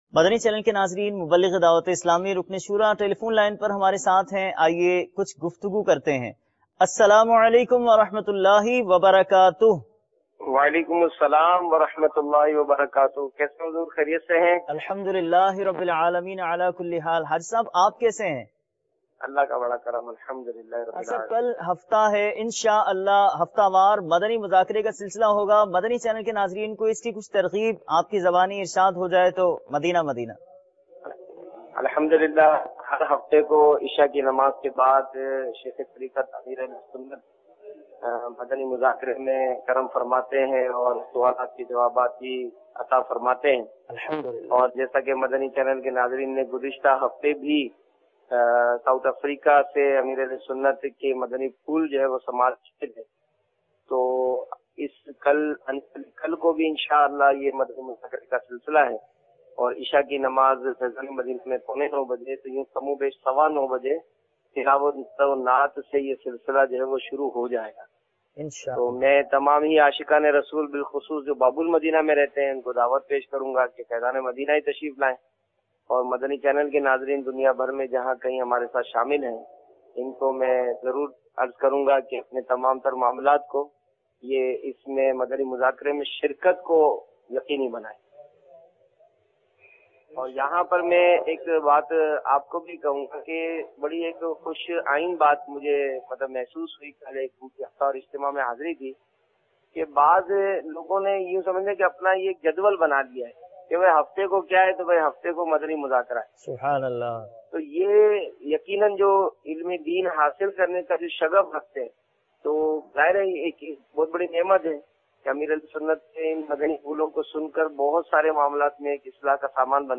News Clip-29 Aug - Live Call, Rukn-e-Shura Ki Madani Muzakra Main Shirkat ki Targheeb Aug 29, 2014 MP3 MP4 MP3 Share نیوز کلپ 29 اگست - رکن شوریٰ کی مدنی مذاکرہ میں شرکت کی ترغیب کے حوالے سے لائیو کال